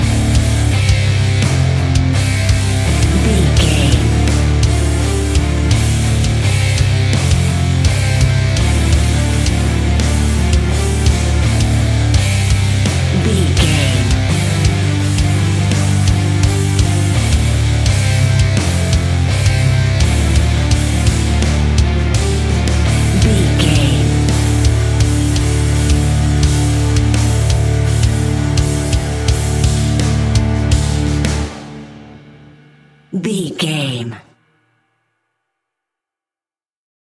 Epic / Action
Fast paced
Aeolian/Minor
hard rock
heavy metal
horror rock
instrumentals
Heavy Metal Guitars
Metal Drums
Heavy Bass Guitars